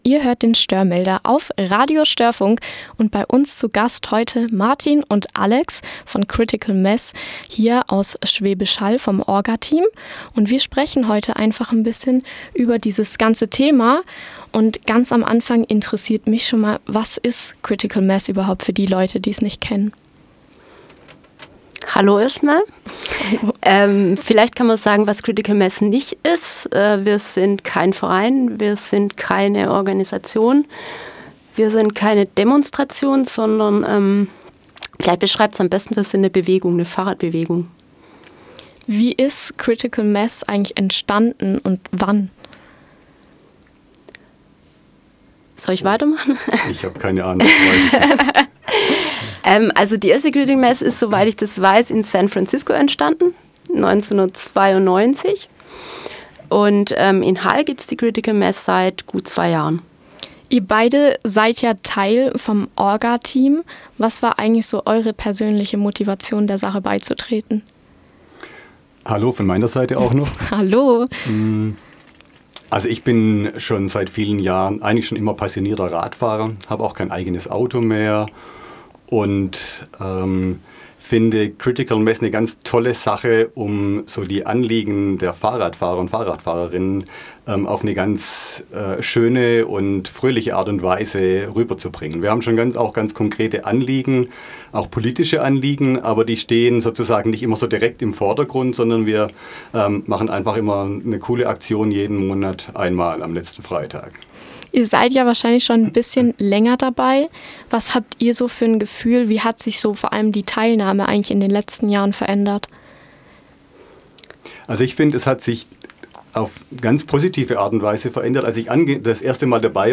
StHörmelder-Interview